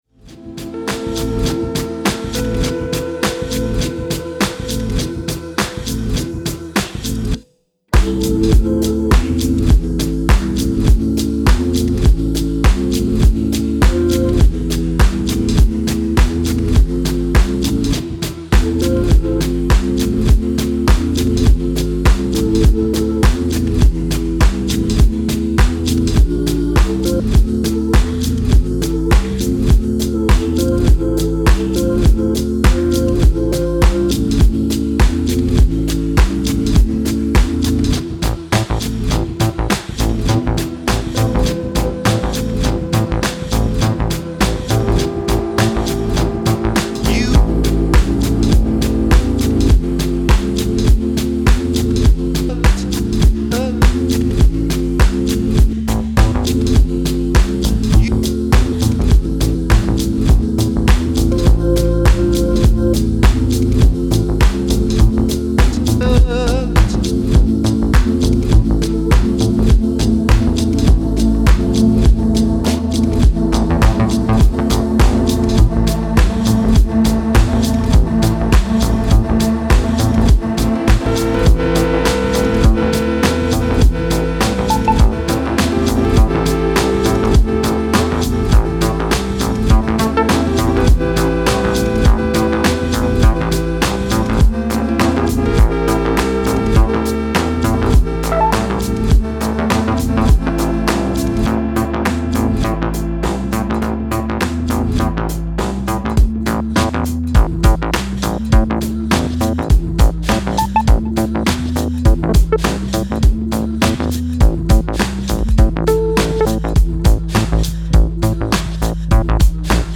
Style: Tech House / Deep House